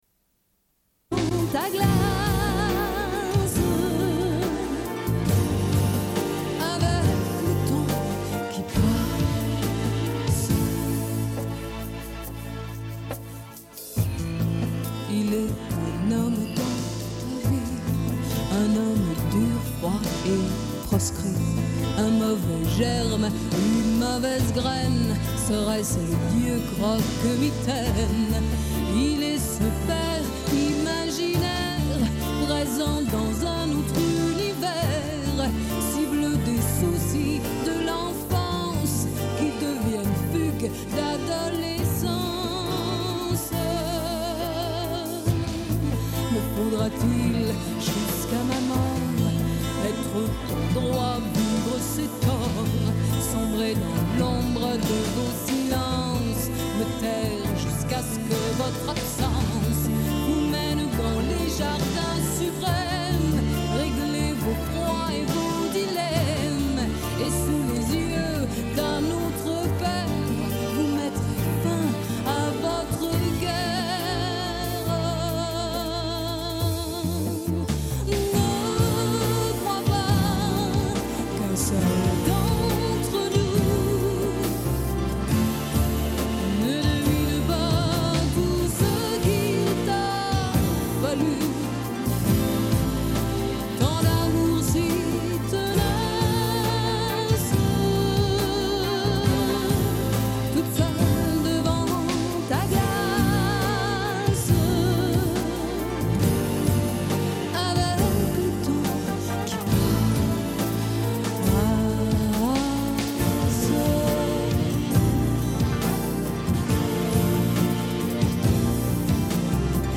Une cassette audio, face B
Radio Enregistrement sonore